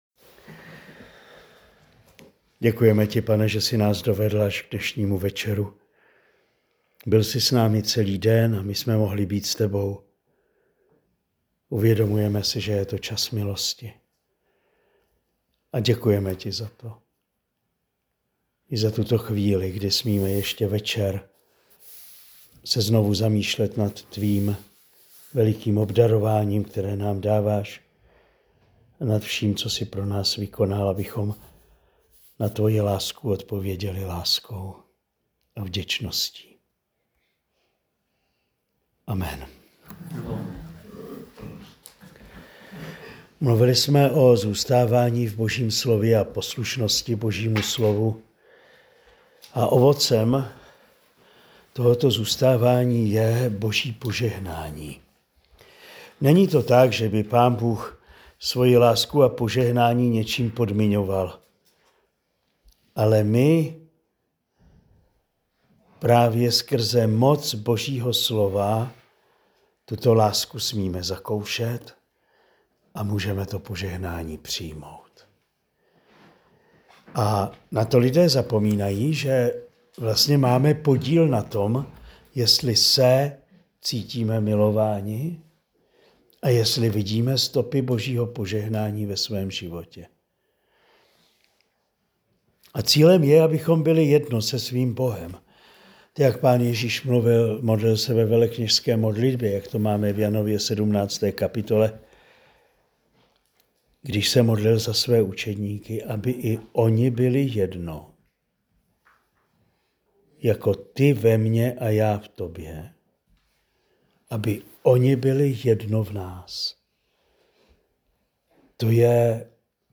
Čtvrtá promluva z duchovní obnovy pro manžele v Kostelním Vydří v únoru 2025.